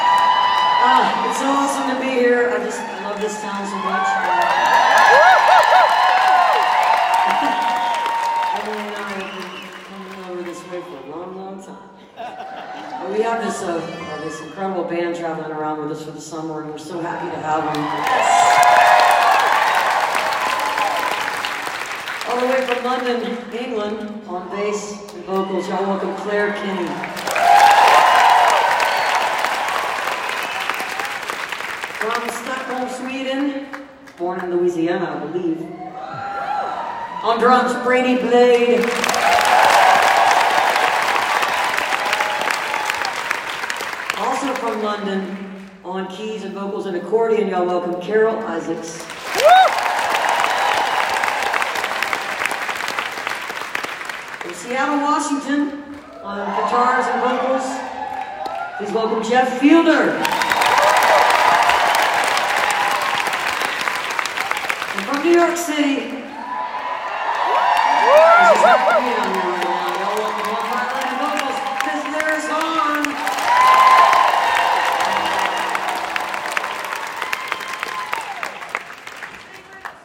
03. band introductions (1:20)